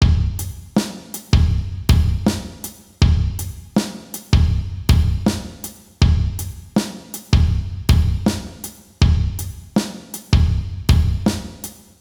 Les sons en dessous de chaque rythme sont respectivement à 80 bpm puis à 120 bpm pour pouvoir les bosser tranquillement et aussi écouter les résultats attendus à vitesse plus élevé.
Ici on travaille la croche deux double sur le 2ème temps.
contre-temps-exo-4-80-bpm.wav